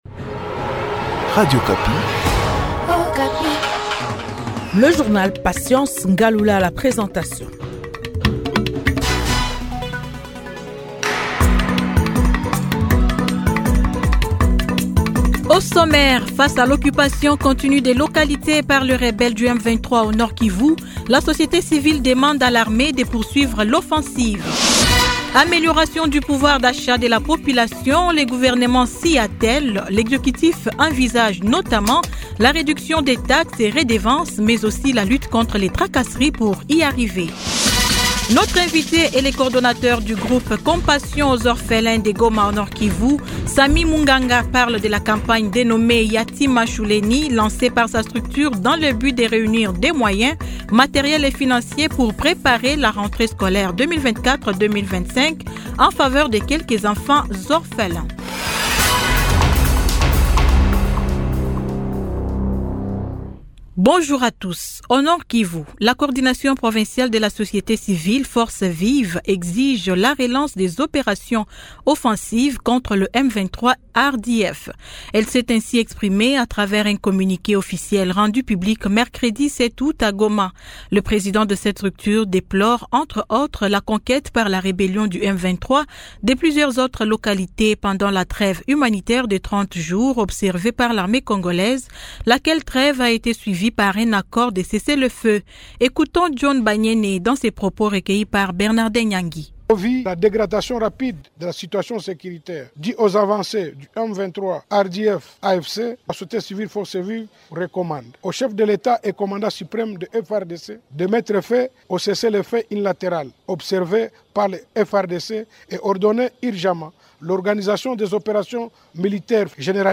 Journal 15H00